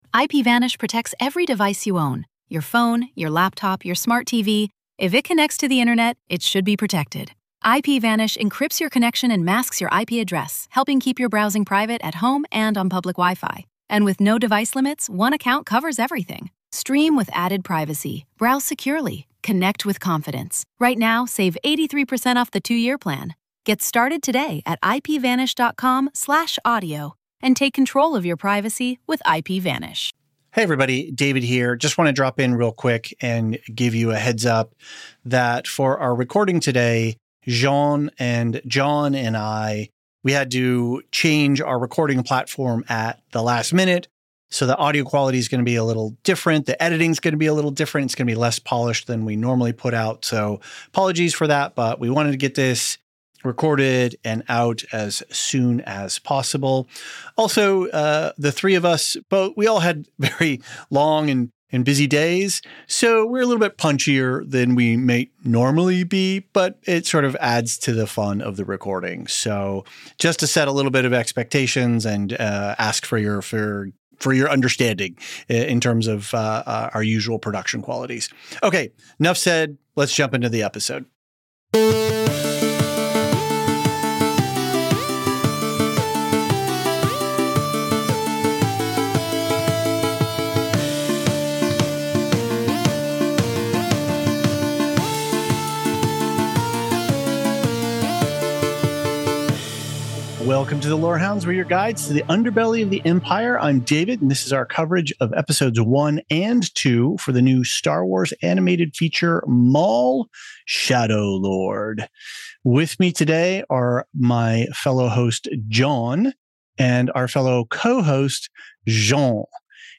A quick note — we had some technical issues with our usual recording setup this week, so the audio quality isn't up to our normal standards, but the conversation is very much worth the listen.